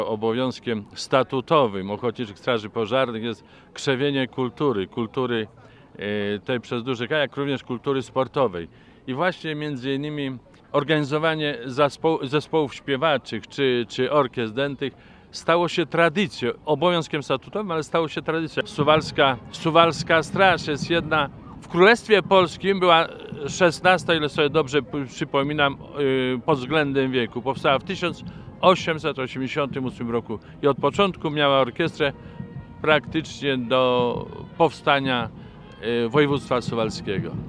XXV Regionalny Przegląd Orkiestr Ochotniczych Straży Pożarnych odbył się w niedzielę (17.06) w Suwałkach.
Jak mówi Tadeusz Chołko, wójt gminy Suwałki, obowiązkiem statutowym strażaków jest popularyzowanie kultury.